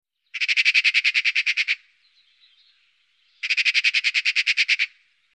Am häufigsten und bekanntesten ist das „Schackern“ wie „schack-schack-schack“. Zu hören sind weiter kürzere, harte Rufe wie „tschjuk“, keckernde Lautreihen wie „chächächä“.
Der Ruf der Elster
ElsterRufe.mp3